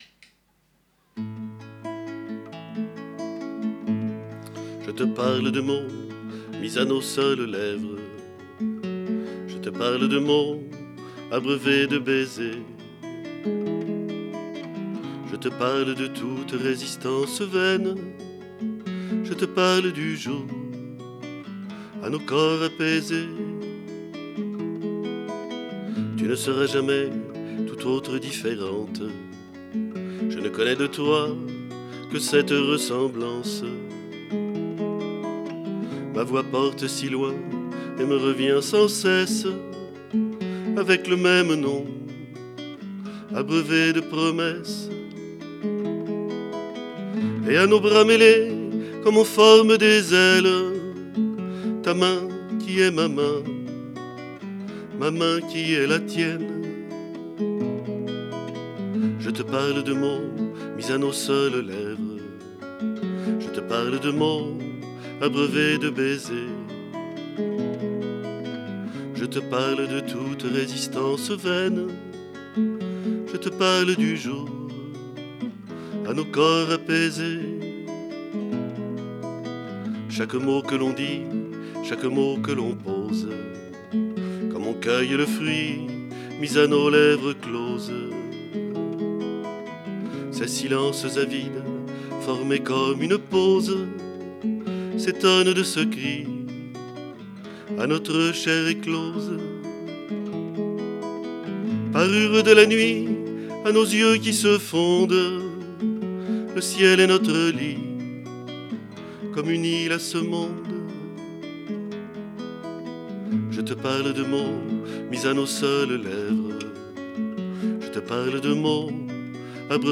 Texte, musique, chant, guitare